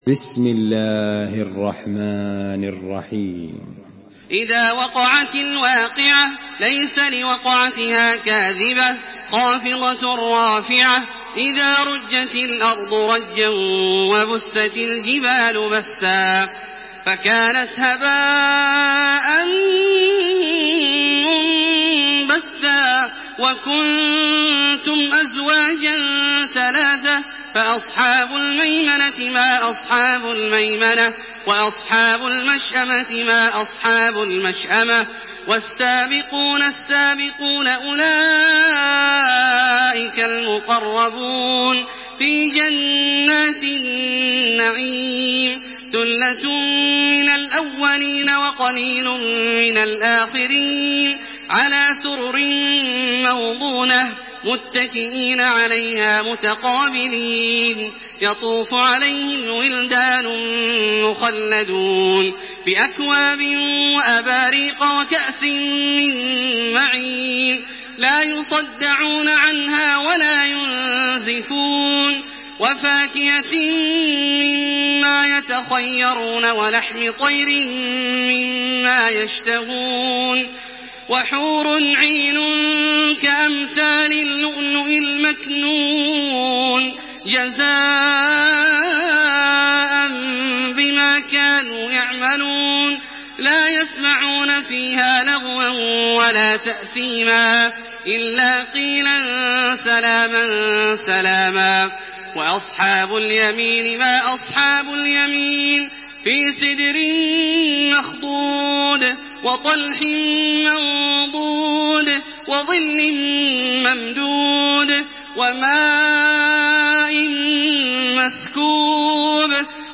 تحميل سورة الواقعة بصوت تراويح الحرم المكي 1428
مرتل حفص عن عاصم